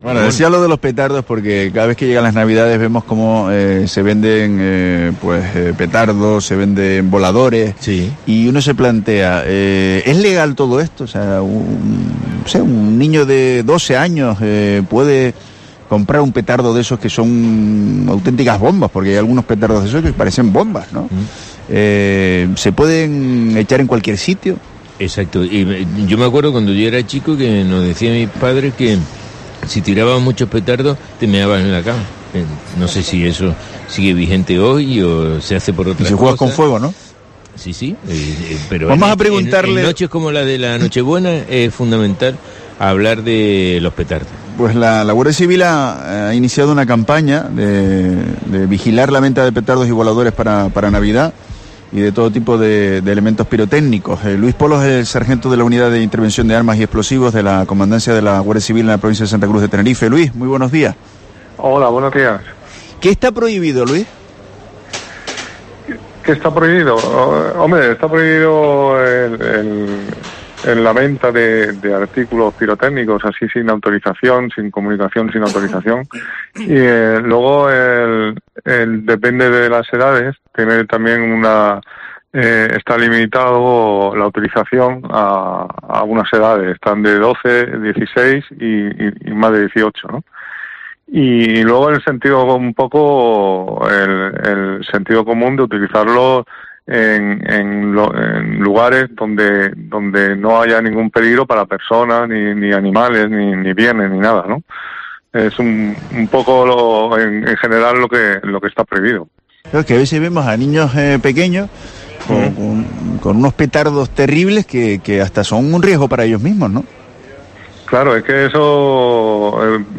ha pasado por los micrófonos de COPE Canarias